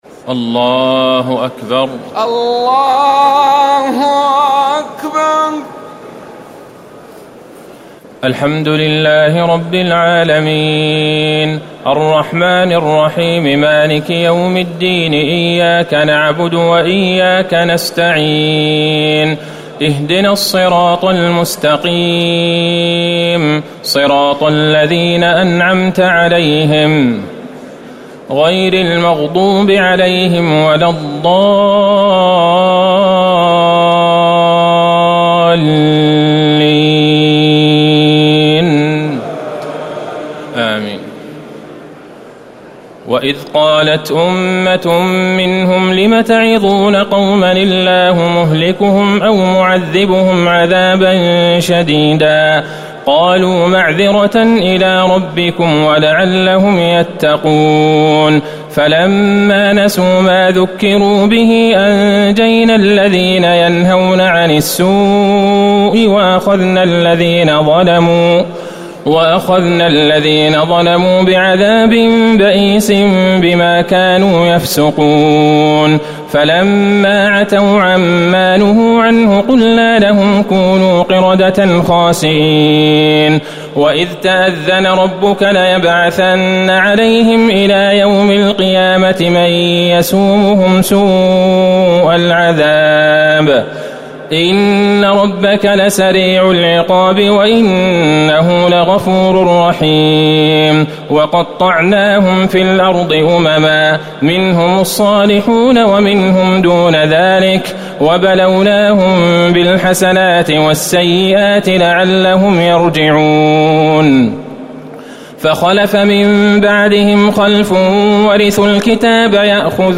تراويح الليلة الثامنة رمضان 1439هـ من سورتي الأعراف (164-206) والأنفال (1-40) Taraweeh 8 st night Ramadan 1439H from Surah Al-A’raf and Al-Anfal > تراويح الحرم النبوي عام 1439 🕌 > التراويح - تلاوات الحرمين